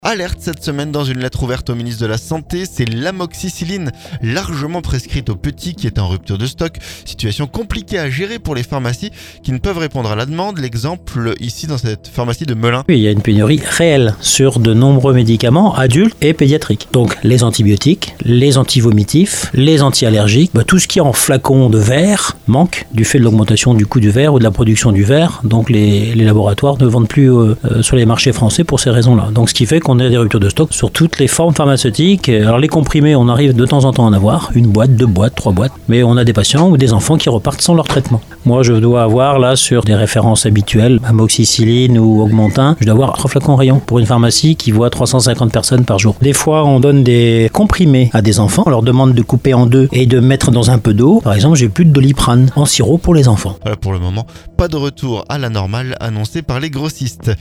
L'exemple avec un pharmacien de Melun.